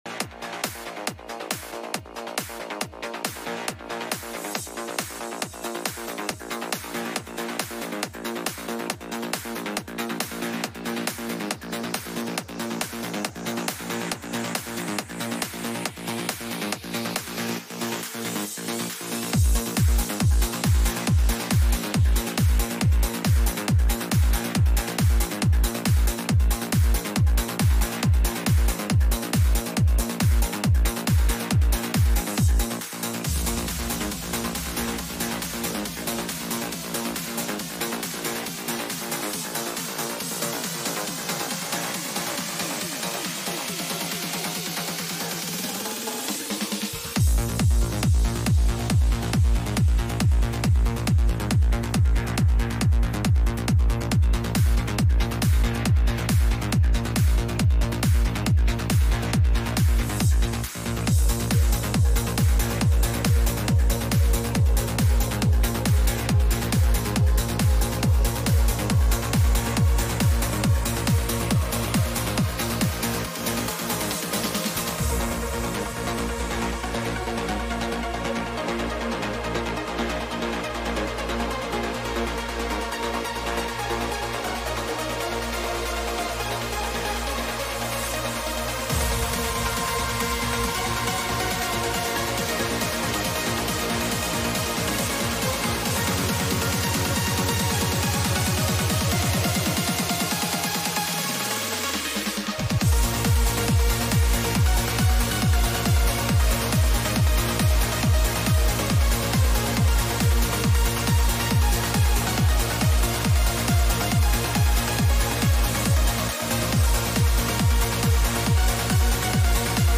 Hard Trance Trance Techno
A Selection of uplifting trance